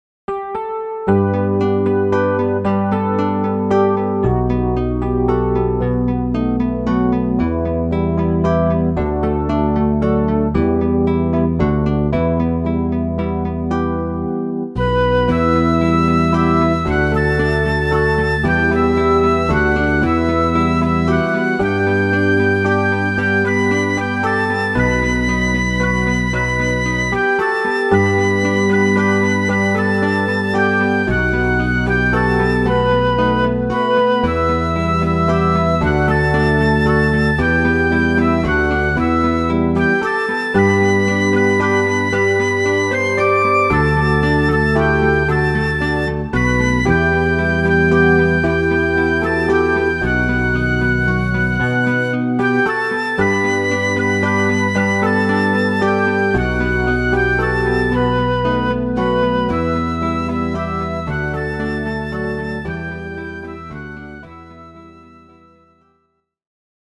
Key: e minor